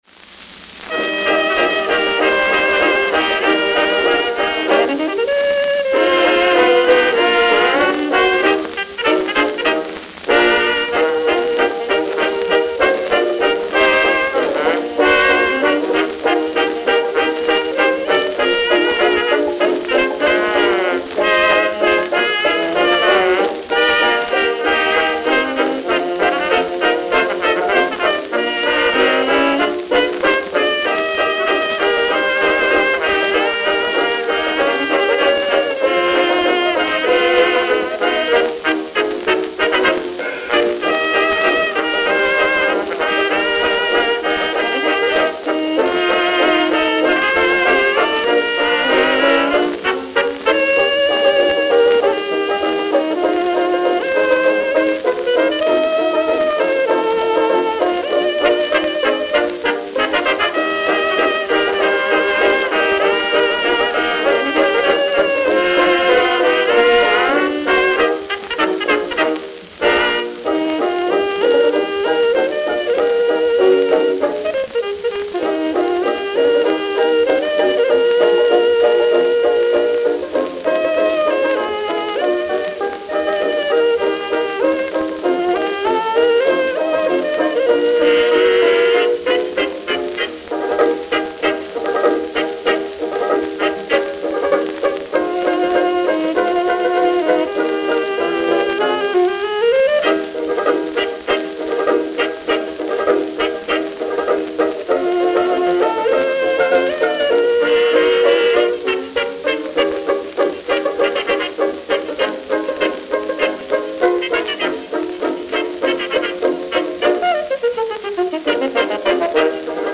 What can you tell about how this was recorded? Note: Very worn. Rumble filtered.